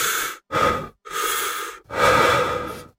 Heavy Breathing
Labored heavy breathing as if after intense physical exertion or fear
heavy-breathing.mp3